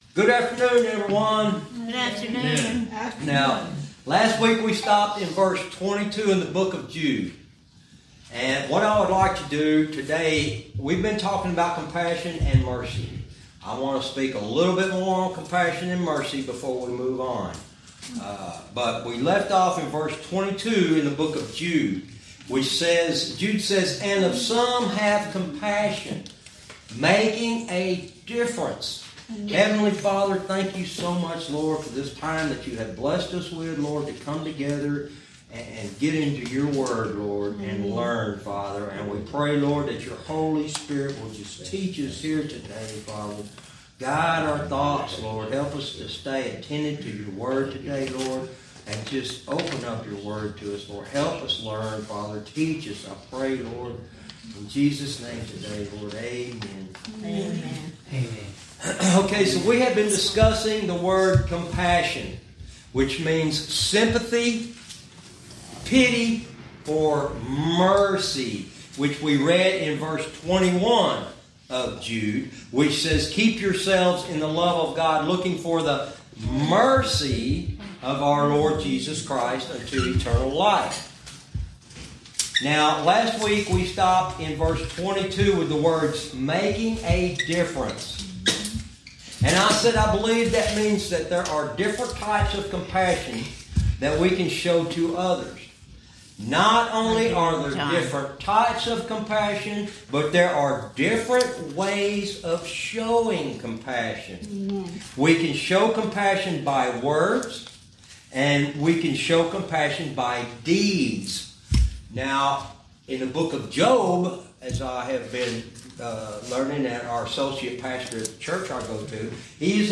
Verse by verse teaching - Jude lesson 101 verse 22